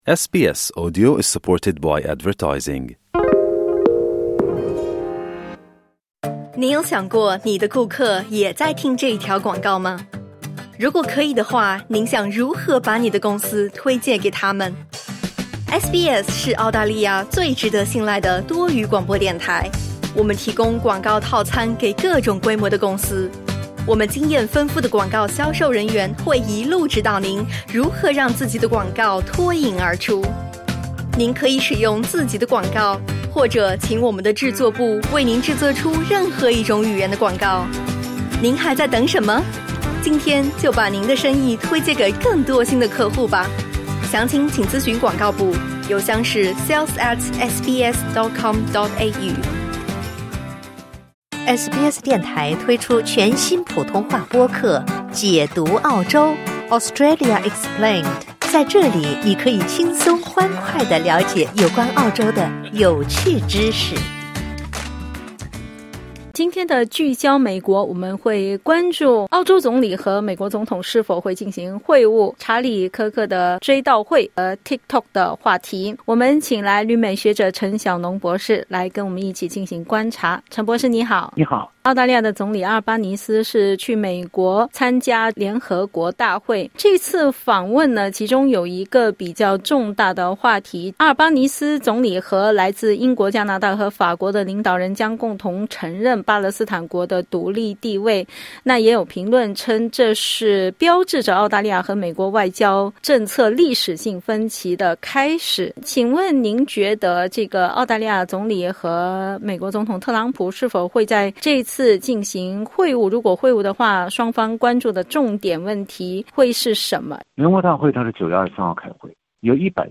点击音频收听详细采访 采访内容仅为嘉宾观点 READ MORE 以色列再次袭击黎巴嫩和加沙 特朗普称 默多克家族将投资TikTok 分享